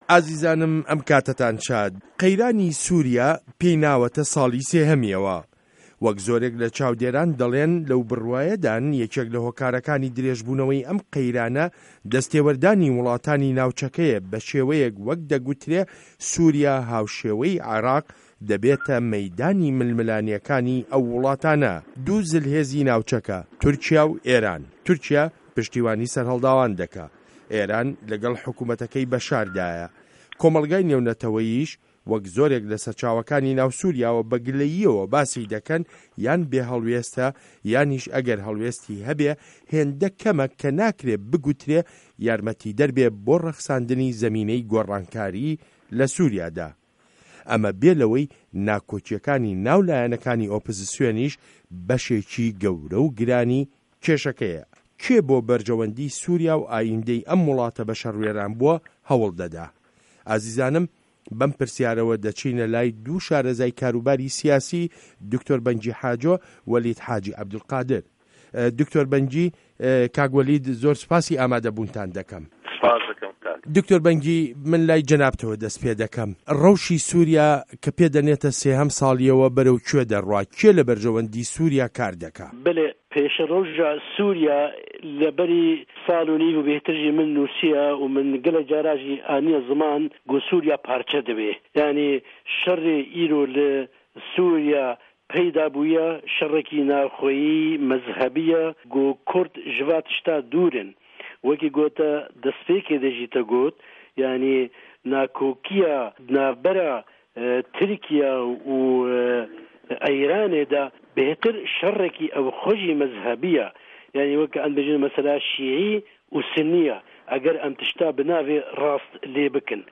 مێزگرد: سوریا